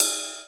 DAN01RIDE.wav